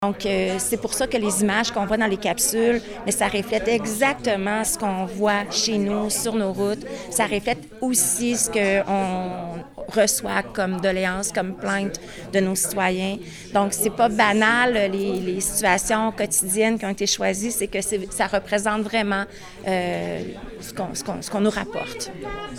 En conférence de presse mardi à l’hôtel de ville, la Ville a présenté trois capsules vidéo sur les bonnes pratiques de conduite à adopter.
La mairesse, Lucie Allard, a résumé ce que l’on retrouve dans les vidéos.